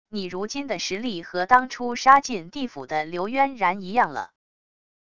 你如今的实力和当初杀进地府的刘渊然一样了wav音频生成系统WAV Audio Player